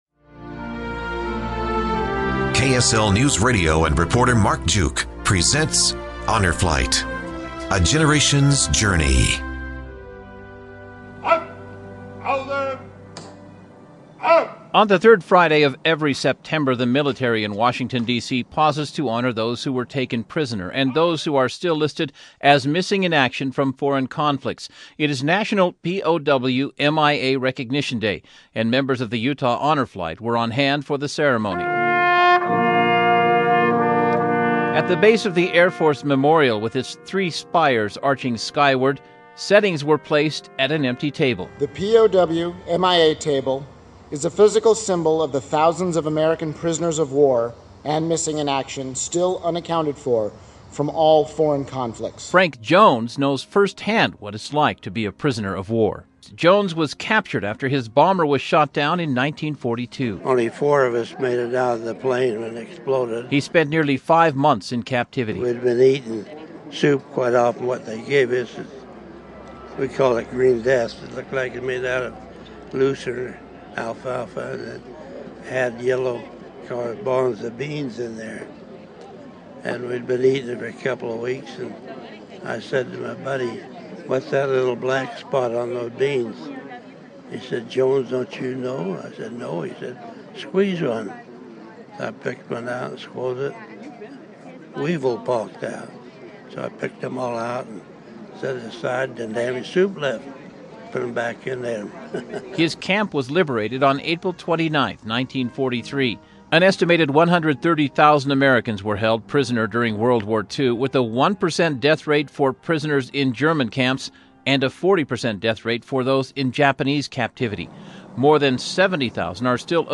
Part 4: Utah Honor Flight / KSL Newsradio documentary